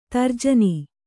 ♪ tarjani